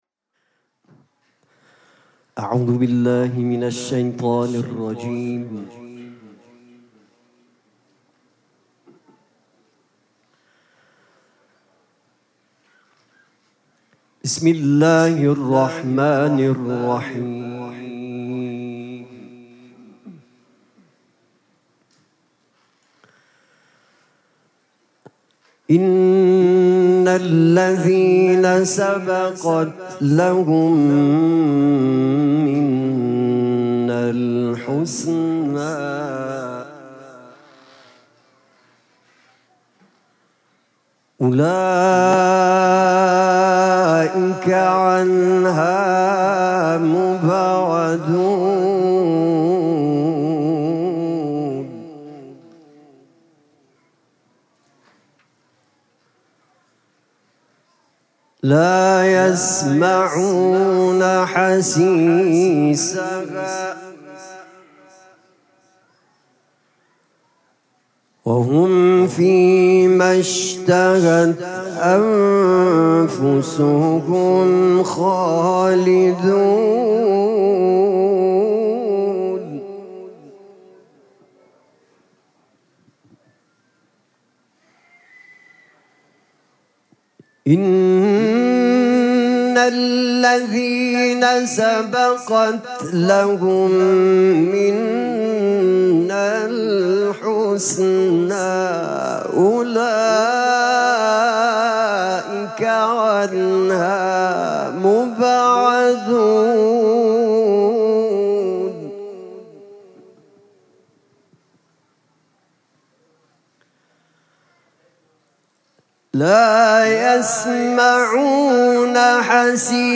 قرائت قرآن
جشن نیمه شعبان